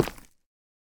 Minecraft Version Minecraft Version latest Latest Release | Latest Snapshot latest / assets / minecraft / sounds / block / deepslate / step2.ogg Compare With Compare With Latest Release | Latest Snapshot
step2.ogg